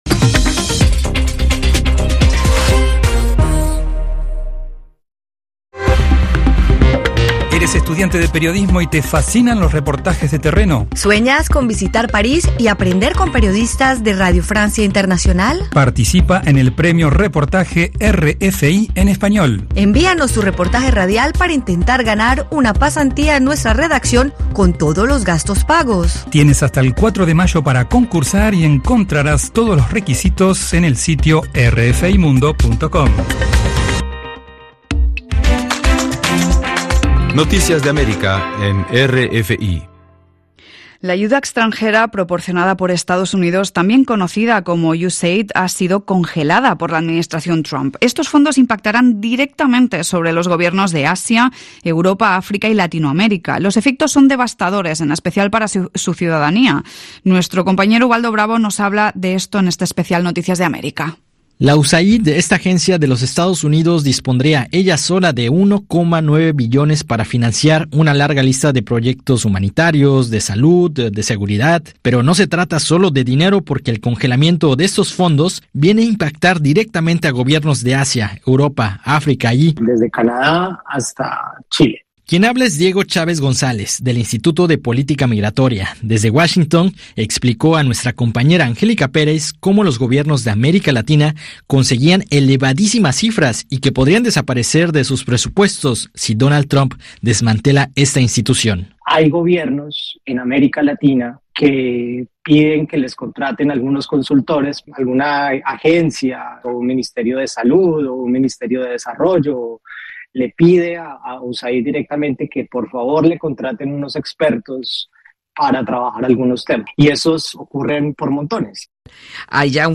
Todos los noticieros de RFI para seguir la actualidad mundial.